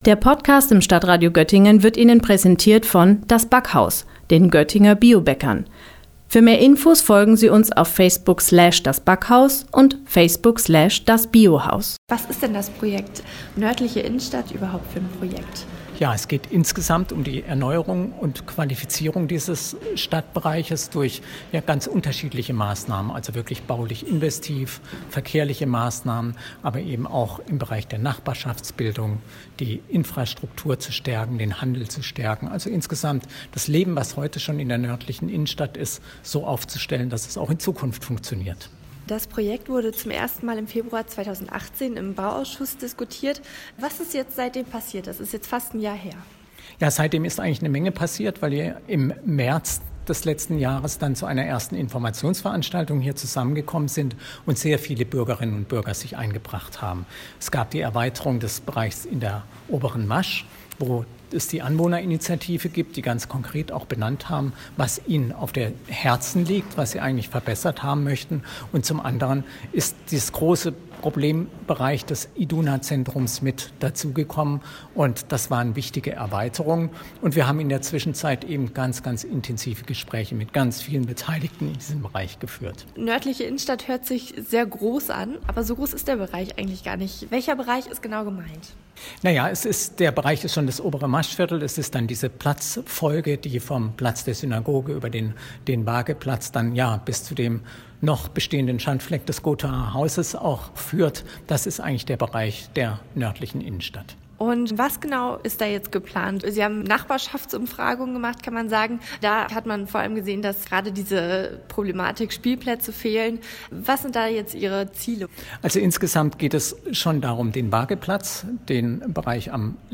über das Projekt „Nördliche Innenstadt“ gesprochen